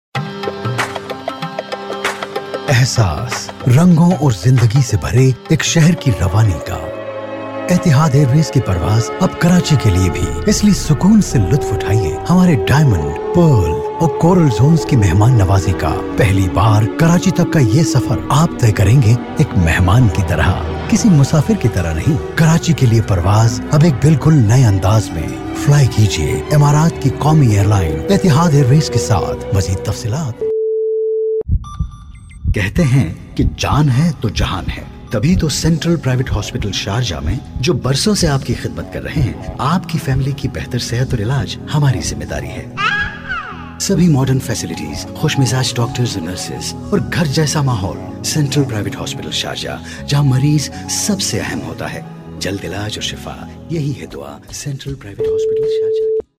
Hindi, Urdu, Indian English, friendly, warm, sexy, dramatic, expressive, versatile, can traslate into Hindi and Urdu, can write concept & scripts, can mix audio also
Sprechprobe: eLearning (Muttersprache):
Voice adaptable to any genre.